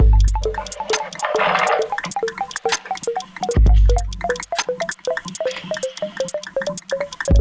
circles break 2.wav